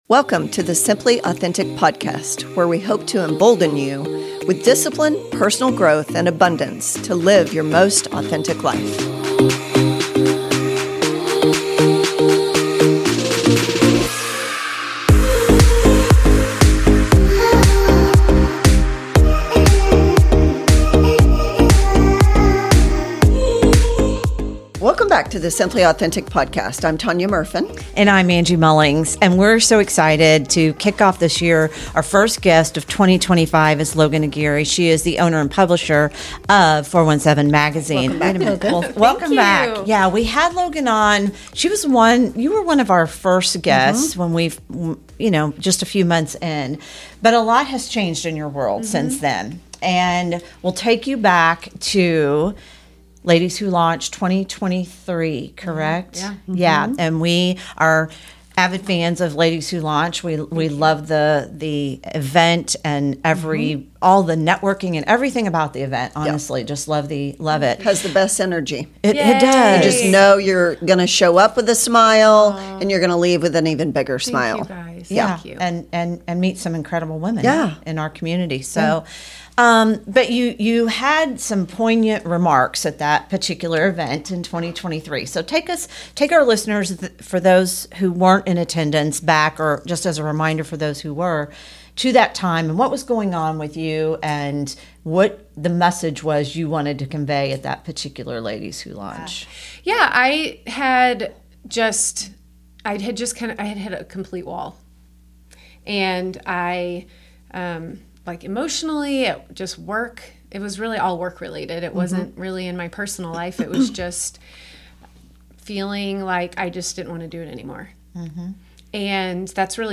Breakthrough and Transform - A Candid Conversation